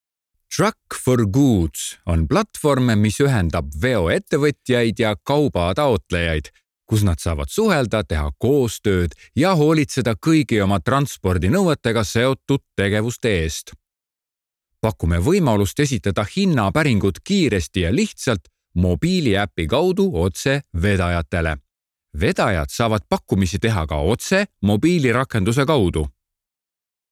Native speaker Male 30-50 lat
Lektor estoński
Nagranie lektorskie w jęz. estońskim